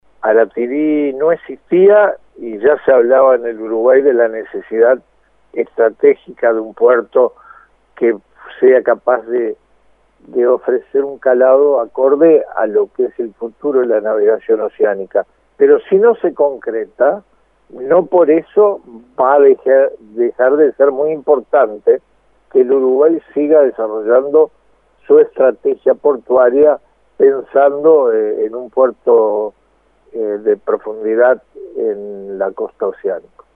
Escuche al ministro Rossi